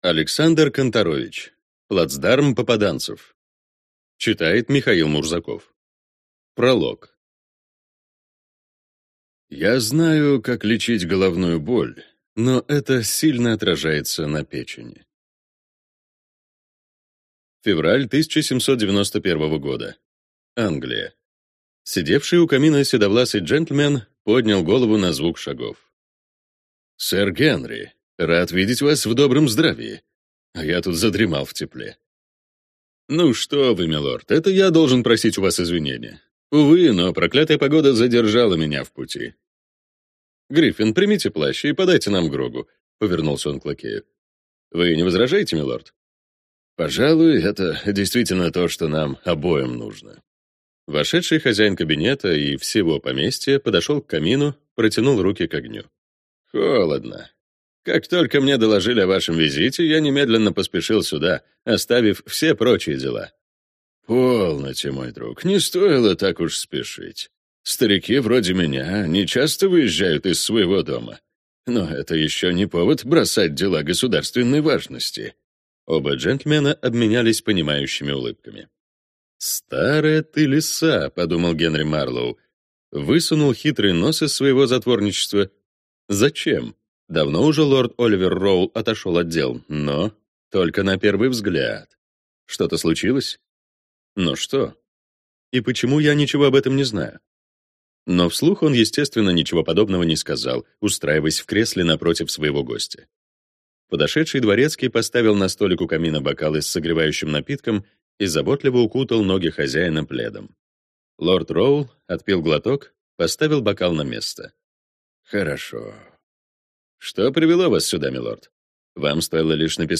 Аудиокнига Плацдарм «попаданцев» | Библиотека аудиокниг